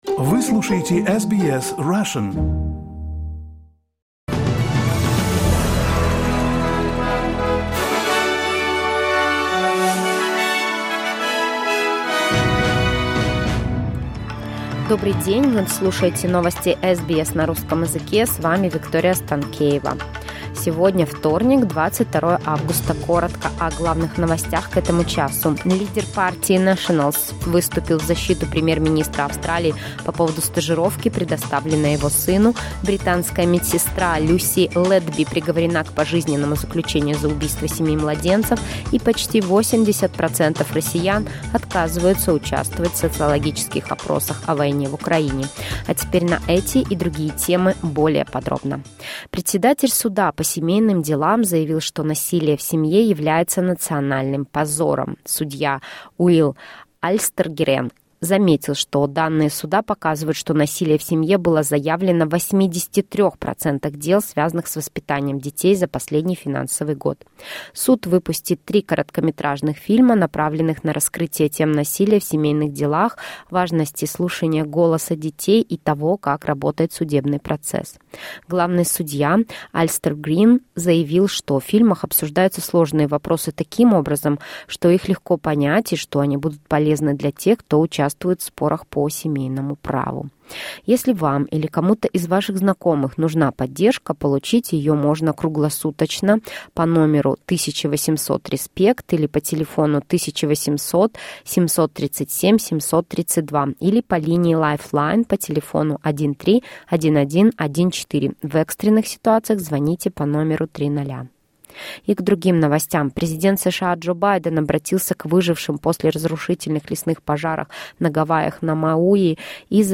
SBS news in Russian — 22.08.2023